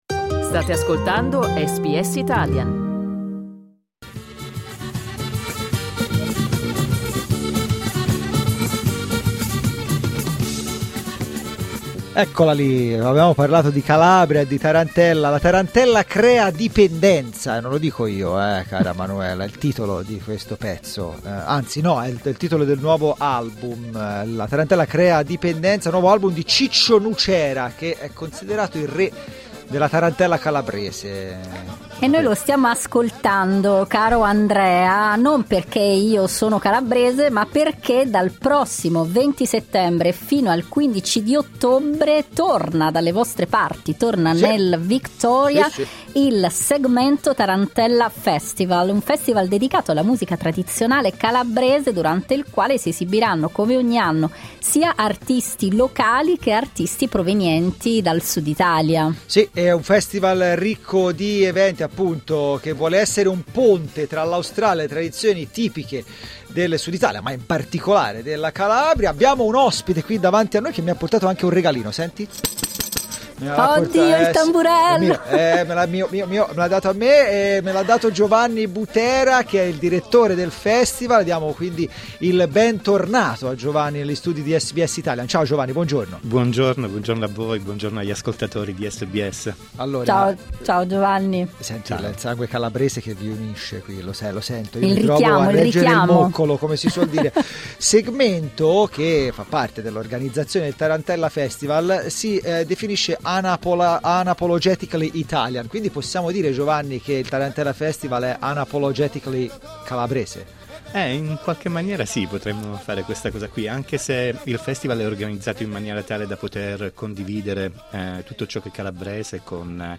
Clicca sul tasto "play" in alto per l'intervista completa The Rustica Project al Tarantella Festival 2023, Melbourne, Australia.